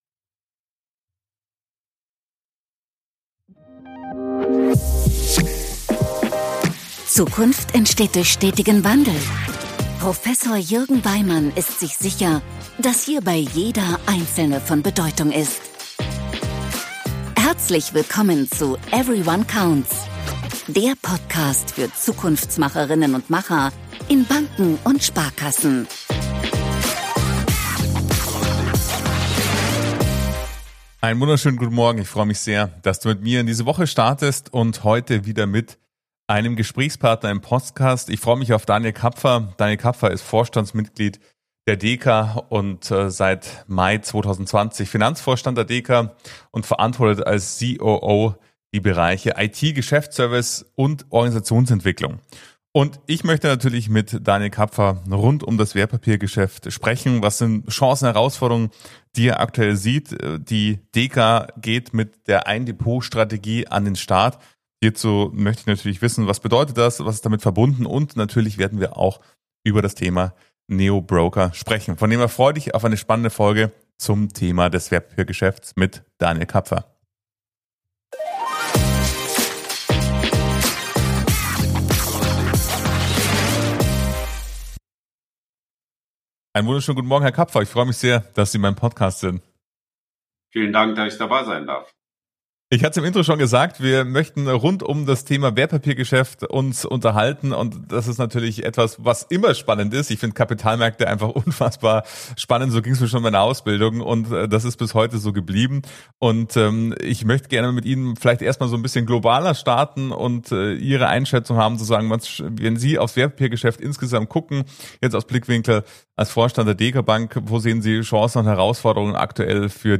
Warum mich dieses Gespräch so begeistert hat?